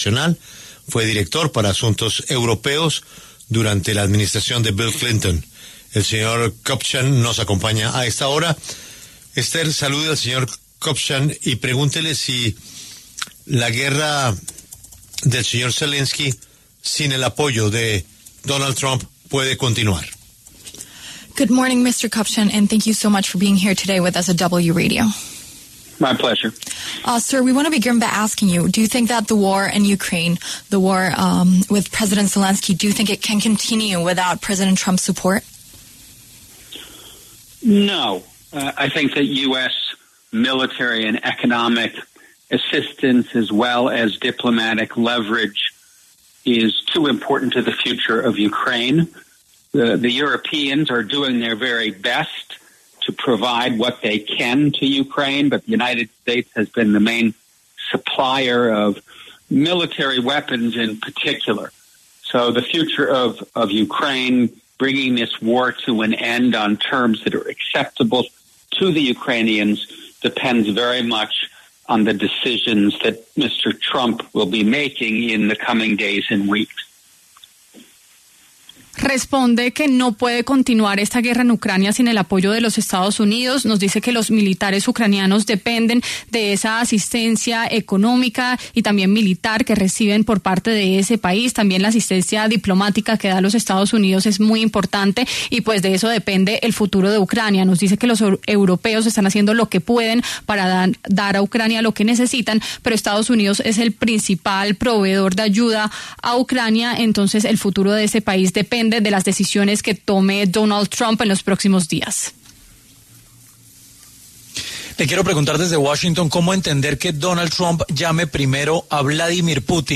Charles Kupchan, quien se desempeñó como asistente especial de Barack Obama, pasó por los micrófonos de La W para hablar sobre la guerra entre Rusia y Ucrania.